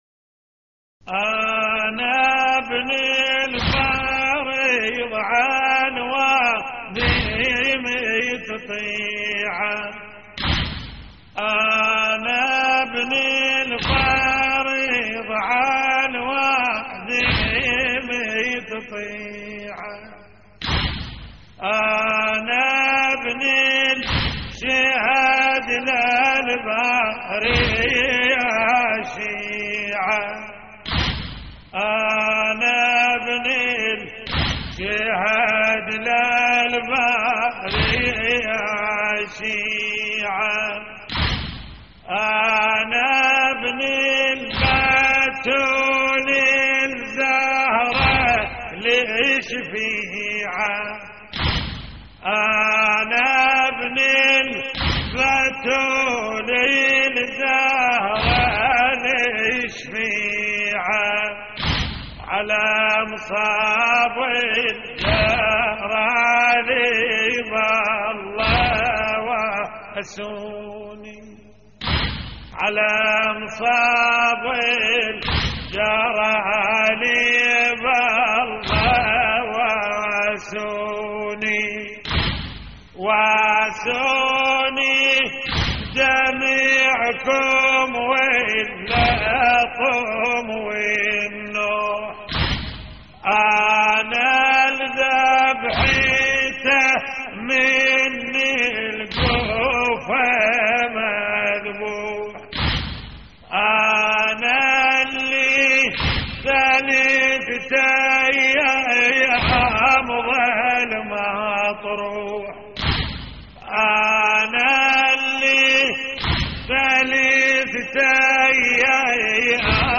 اللطميات الحسينية أنا ابن الفرض عالوادم تطيعه - استديو